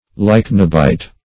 Search Result for " lychnobite" : The Collaborative International Dictionary of English v.0.48: Lychnobite \Lych"no*bite\, n. [Gr. ly`chnos a lamp + bi`os life.] One who labors at night and sleeps in the day.